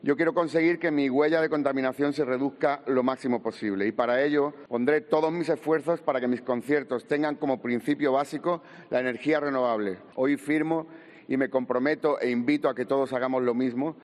"A veces sueña tu valentía con mi alegría; a veces sueña el planeta que le queremos", ha recitado Alejandro Sanz, quien intervino en la ceremonia de apertura del tramo de Alto Nivel de la Cumbre del clima.